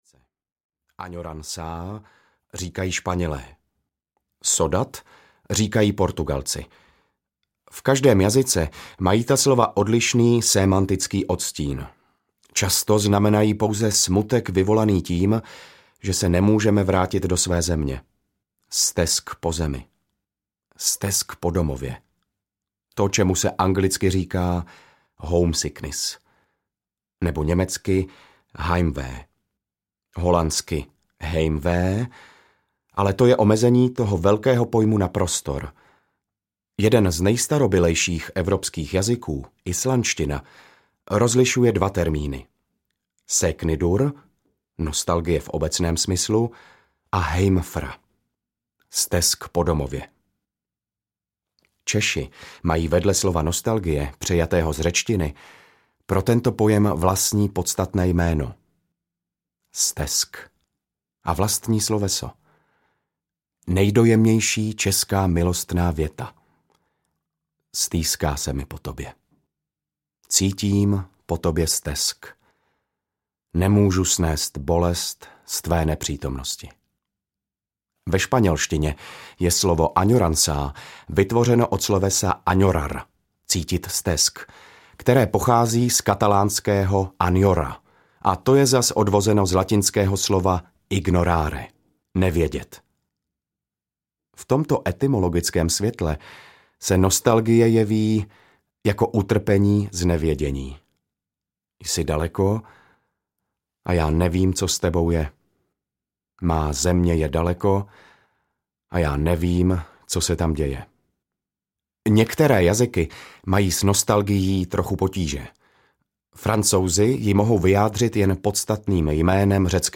Nevědění audiokniha
Ukázka z knihy
• InterpretRadúz Mácha